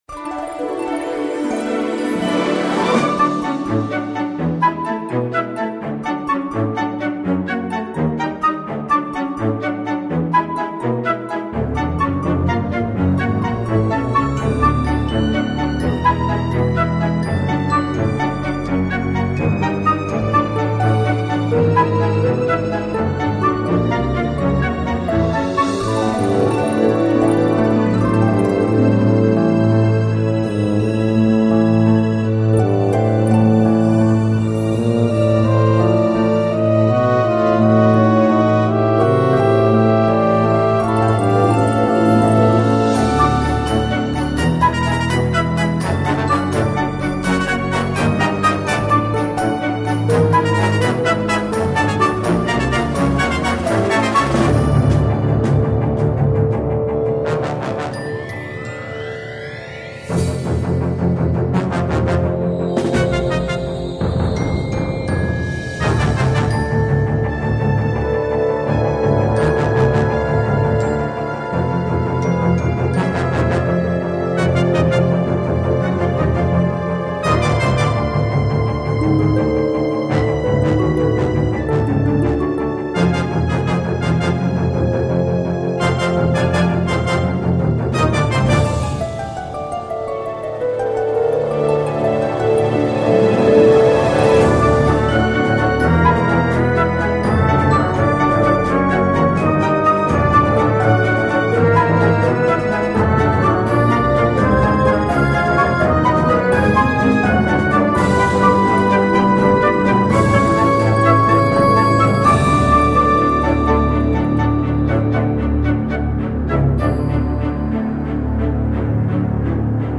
Сказочная фоновая музыка - свежий вариант для прослушивания